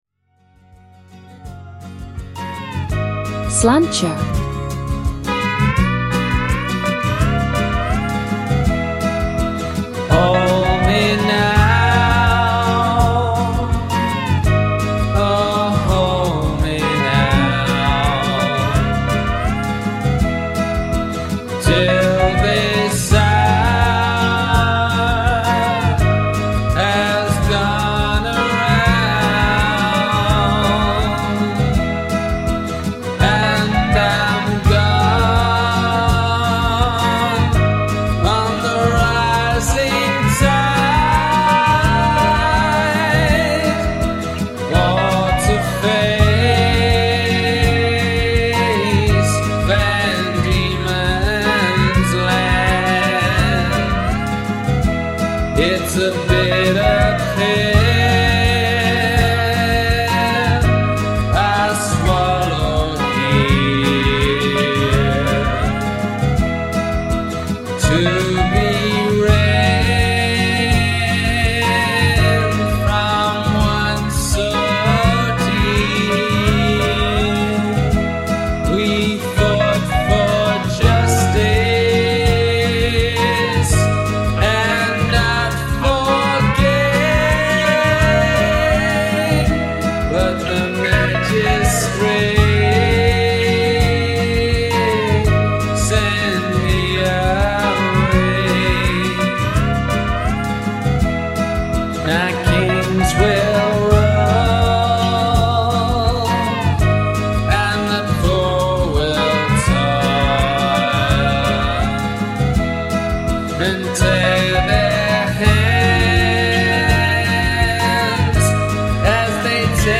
This mashup features the spoken intro